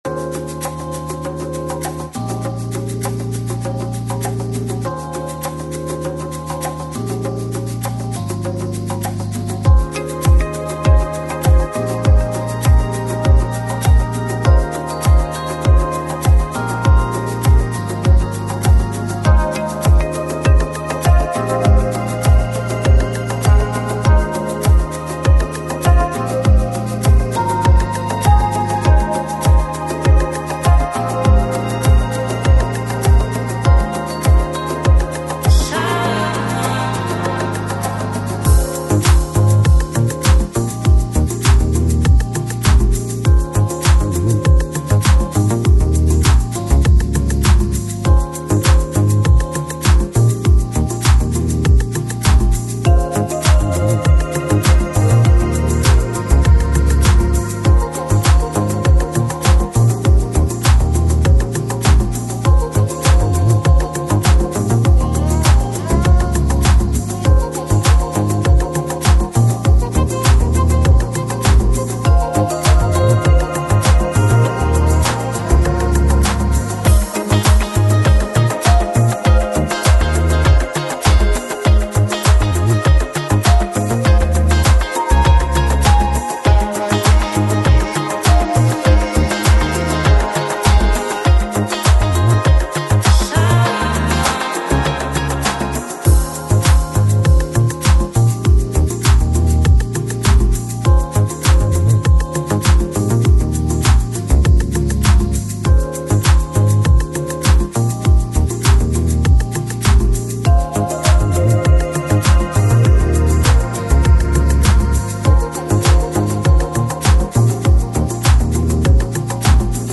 AAC Жанр: Lounge, Chill Out, Downtempo Продолжительность